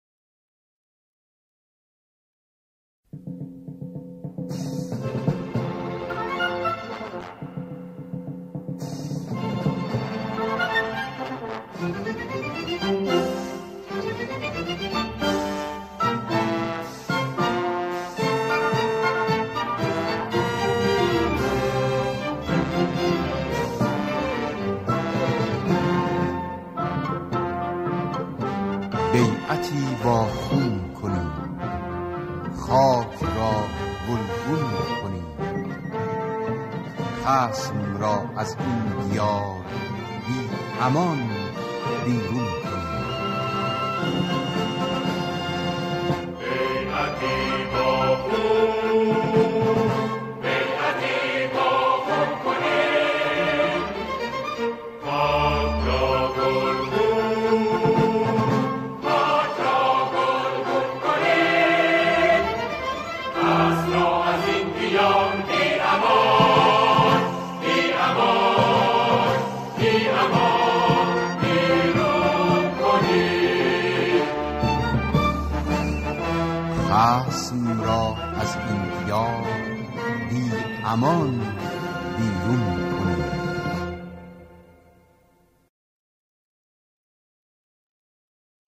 آنها در این قطعه، شعری را درباره دفاع مقدس همخوانی می‌کنند.